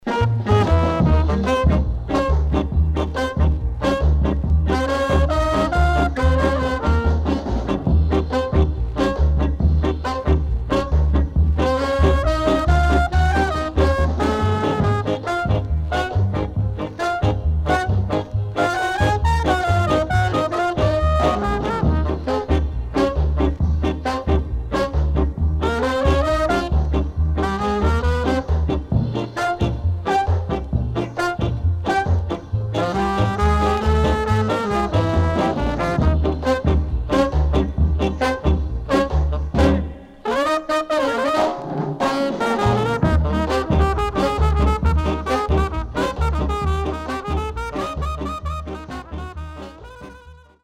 卓越したブロウによるスリリングな演奏は、聴き所満載の極上ジャマイカンセッション!